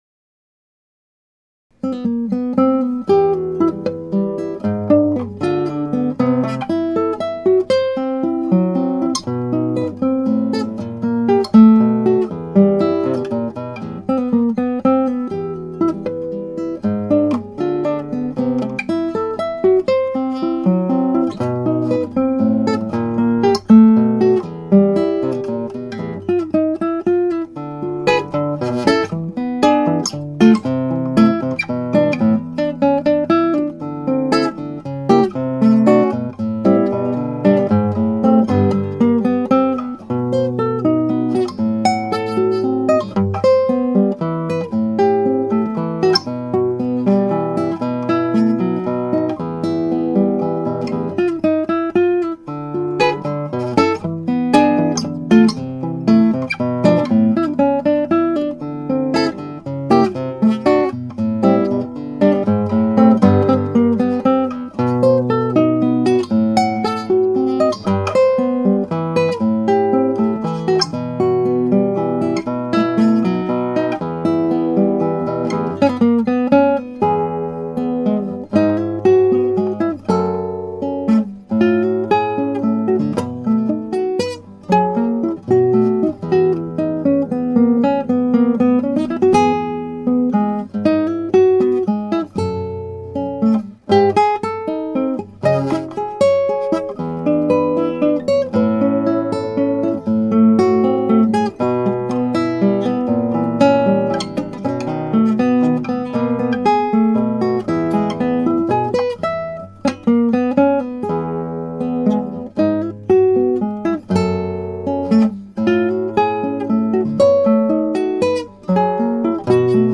(アマチュアのクラシックギター演奏です [Guitar amatuer play] )
この曲はメロディーや和音進行など表現方法を変えたリズムが重要な曲です。
普通はもっと早く演奏されるが今はこれが限界。